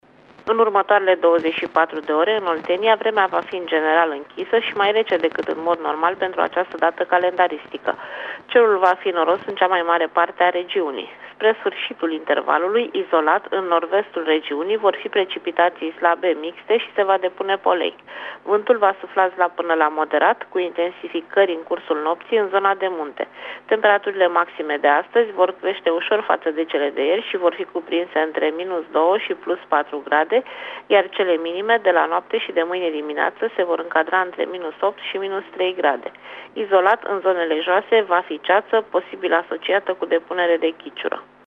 Prognoza meteo 21 decembrie (audio)